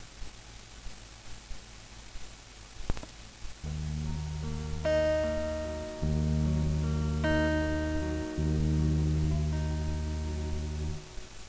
Вот собсна звукосниматель